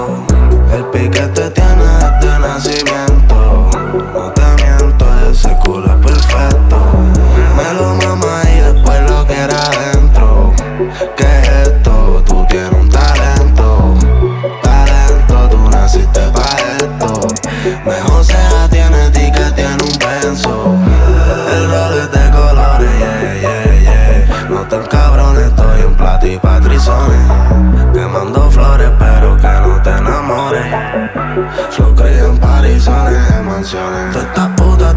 Urbano latino Latin
Жанр: Латино